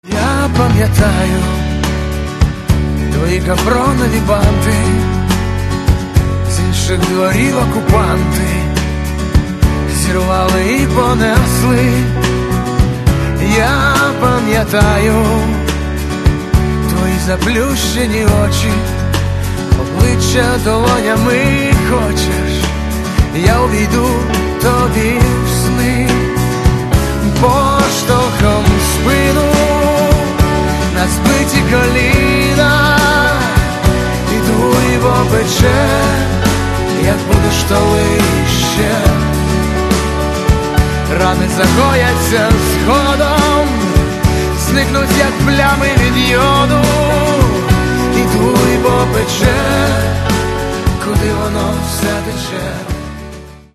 Каталог -> Рок и альтернатива -> Поэтический рок